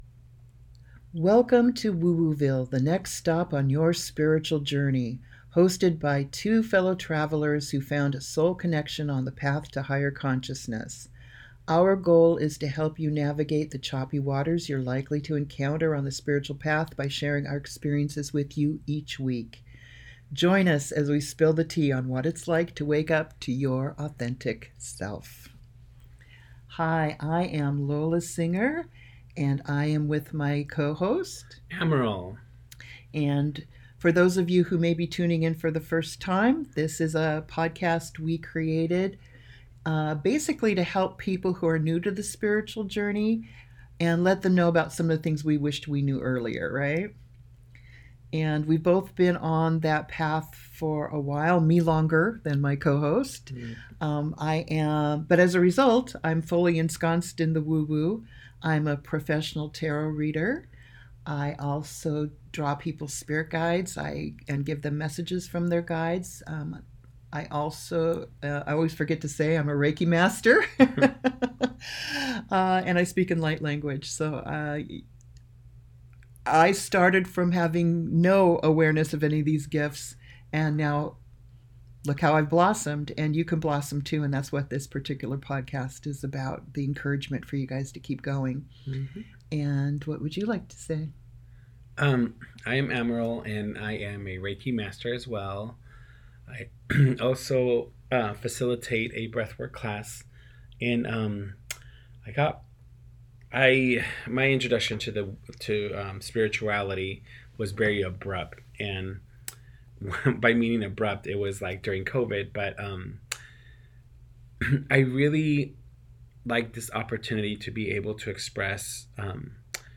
From breaking free of old stories and martyrdom traps to rediscovering unconditional love for yourself, this heartfelt conversation reminds us that true transformation begins within.